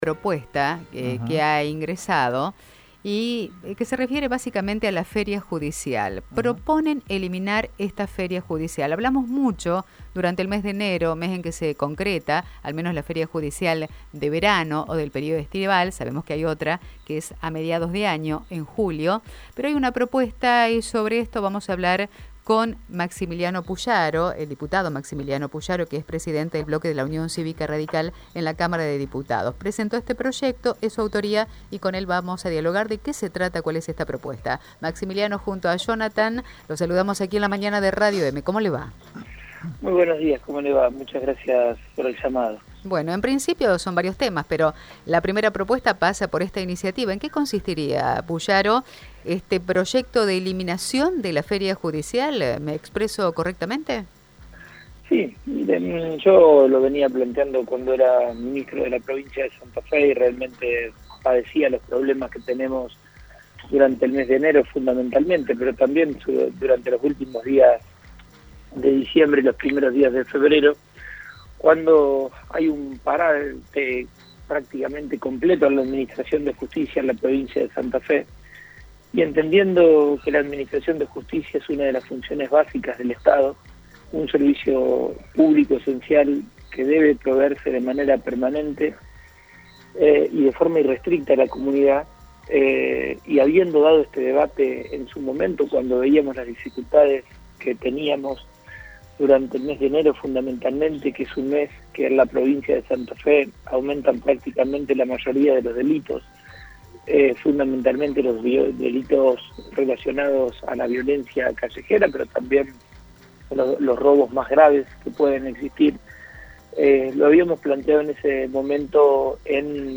Maximiliano Pullaro en Radio EME: